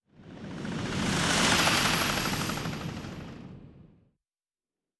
Sci-Fi Sounds / Movement / Fly By 04_3.wav
Fly By 04_3.wav